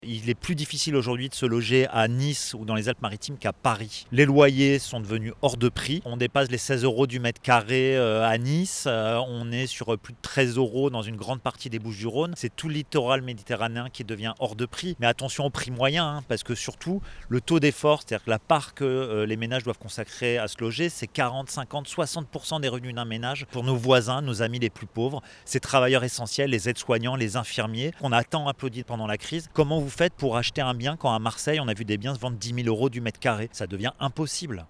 Reportage